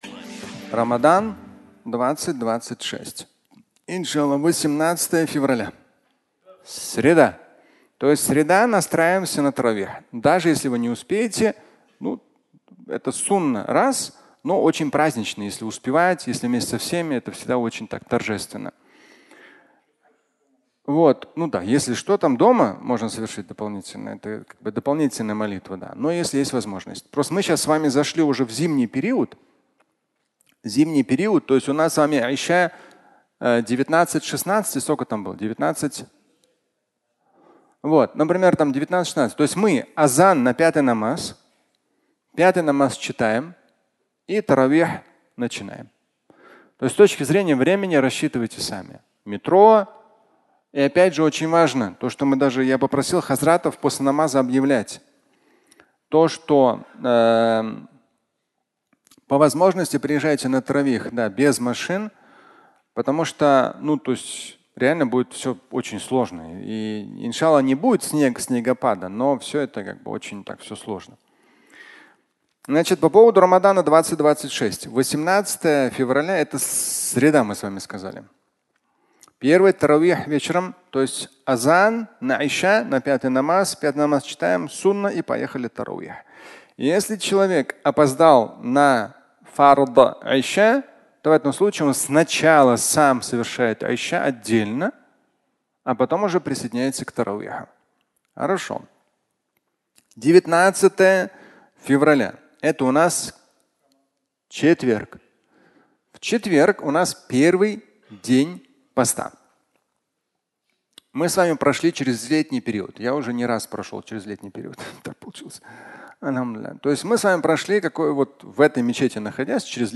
Рамадан 2026 (аудиолекция)